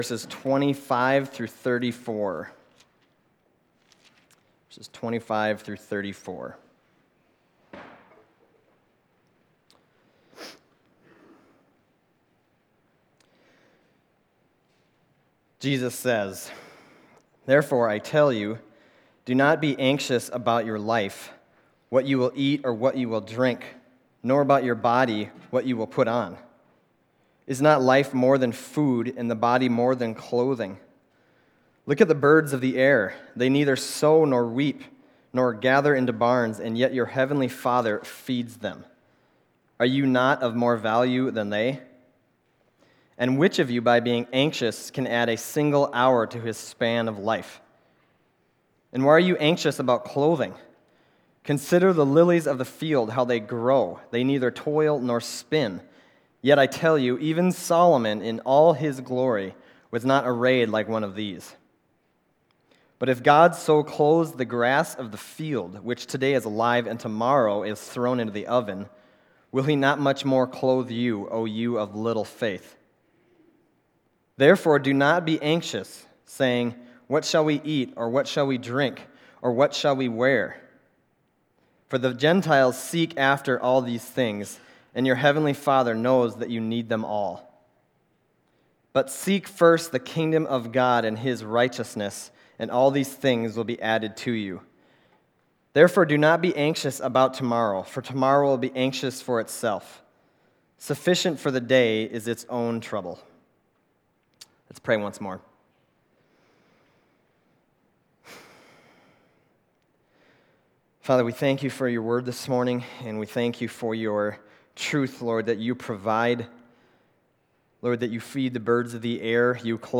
Other Passage: Matthew 6:24-34 Service Type: Sunday Morning Matthew 6:24-34 « Have You Become Dull of Hearing?